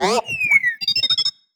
sci-fi_driod_robot_emote_10.wav